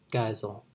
sooss GHY-zəl, zoyss -;[2][3][4] March 2, 1904 – September 24, 1991)[5] was an American children's author, illustrator, animator, and cartoonist.
En-us-Geisel.ogg.mp3